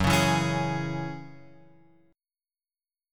F#7sus2 chord {2 4 2 x 2 4} chord